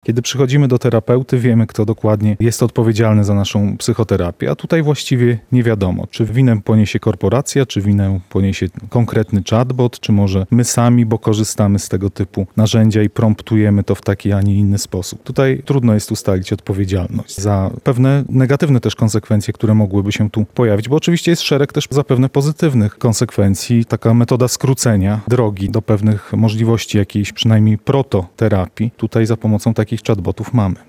O szansach i zagrożeniach wynikających z rozwoju sztucznej inteligencji rozmawiają uczestnicy konferencji "mAIówka" na Uniwersytecie Marii Curie-Skłodowskiej.